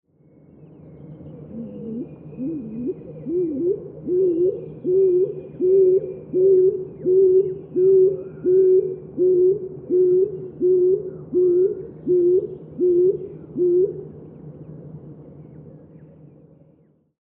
Muuttolintukalenteri: Uuttukyyhky ja sepelkyyhky
Keväiset huhuilijat uuttukyyhkyn ja sepelkyyhkyn tunnistaa helposti niiden kaksi- ja viisitavuisten säkeiden avulla.